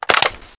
assets/pc/nzp/sounds/weapons/browning/topopen.wav at 29b8c66784c22f3ae8770e1e7e6b83291cf27485
topopen.wav